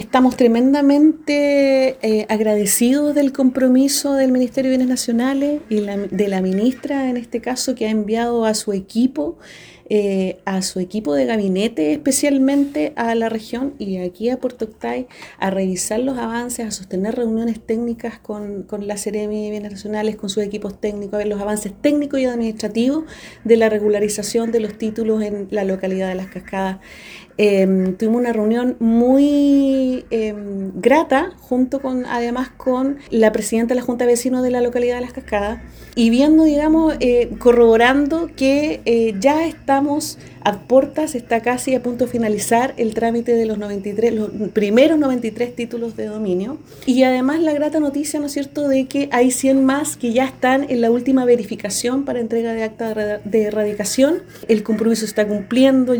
La Alcaldesa (S) de Puerto Octay, Gloria Valderas, señaló que se han cumplido los compromisos adquiridos por las autoridades centrales, donde se han sostenido distintas reuniones con los beneficiarios para finalizar los trámites de la entrega de los primeros títulos de dominio.